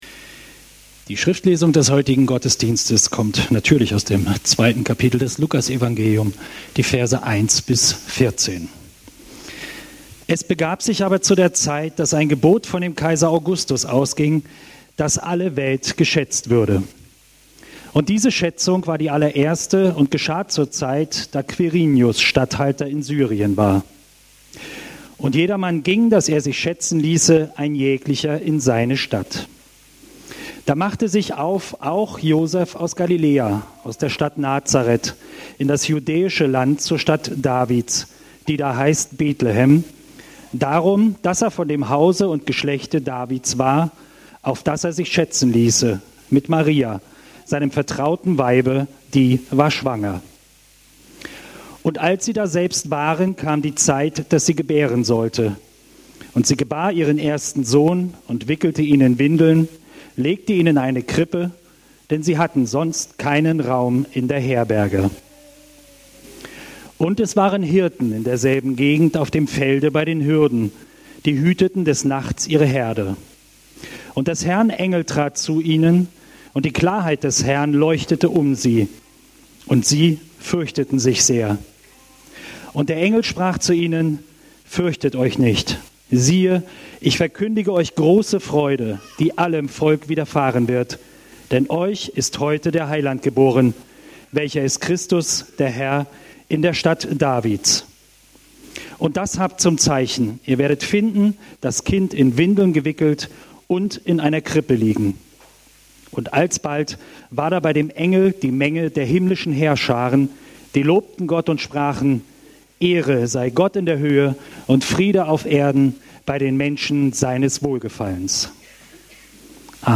Familien-Weihnachtsgottesdienst (16:00 Uhr)
Schriftlesung aus Luk 2, 1-14: